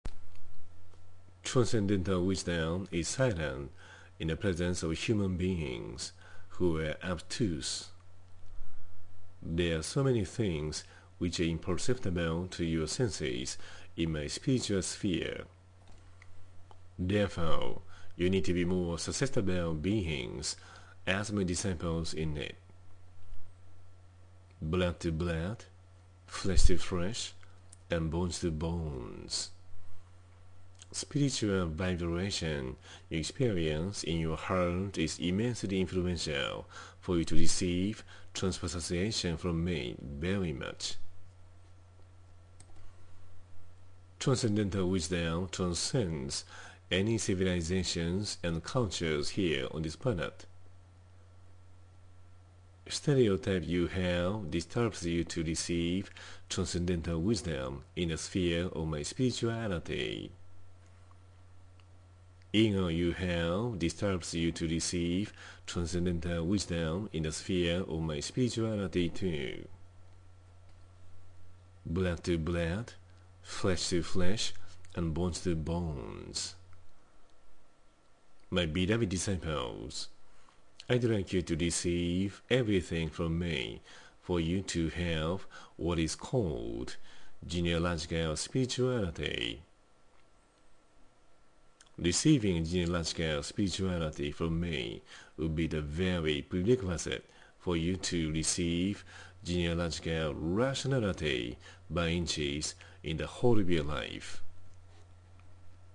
⇒　Blood to blood, flesh to flesh and bones to bones Ⅲ（英語音声講義）